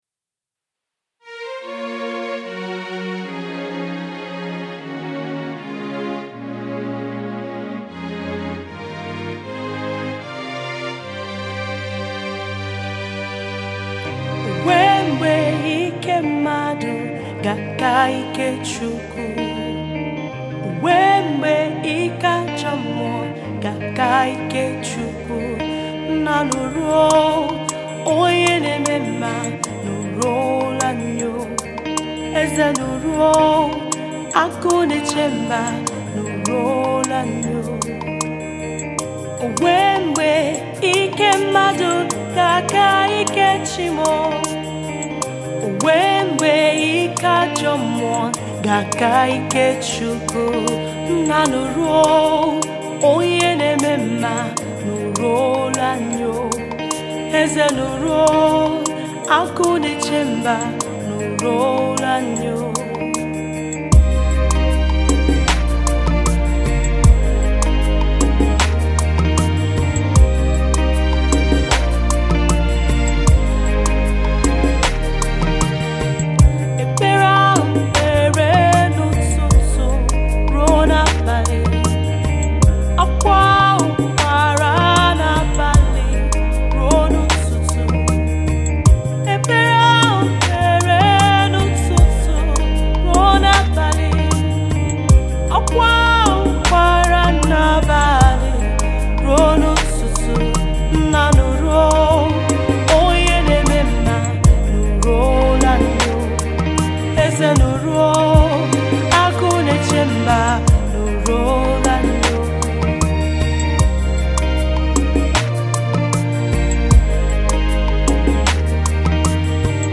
A song of prayer